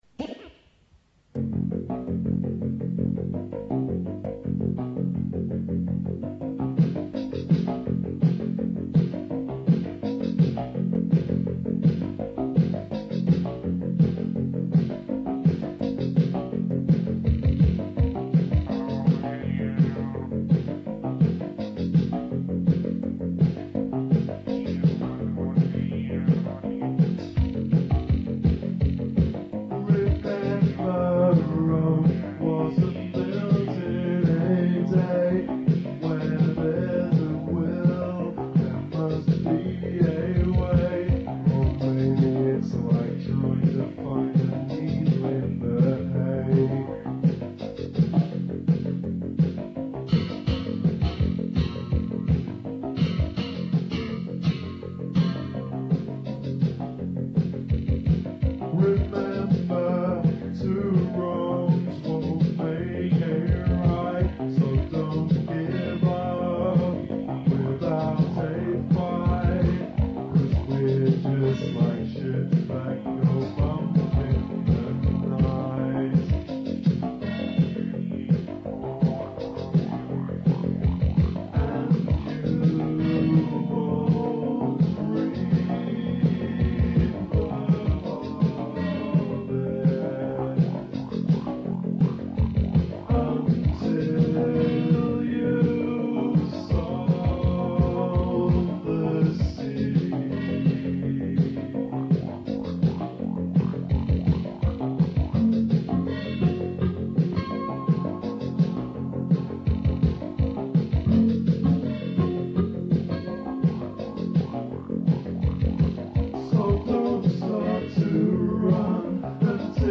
Unreleased '83 Demo